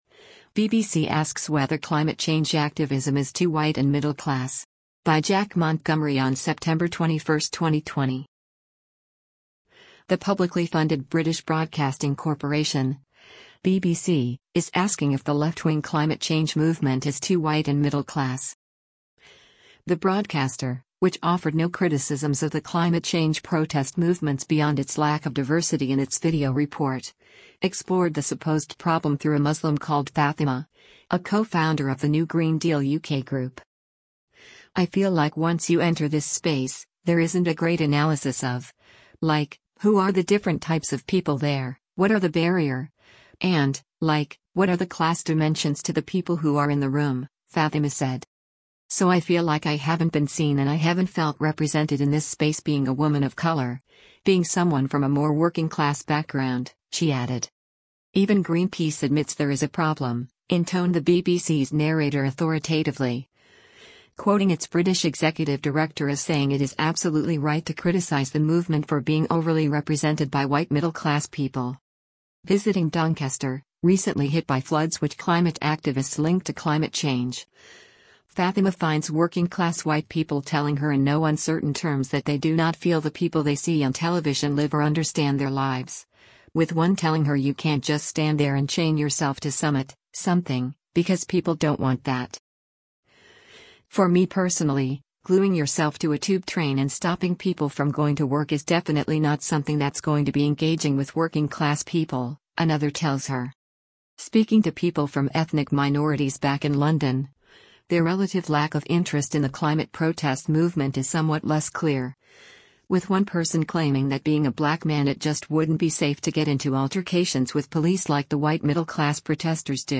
“For me personally, glueing yourself to a Tube train and stopping people from going to work is definitely not something that’s going to be engaging with working-class people,” another tells her.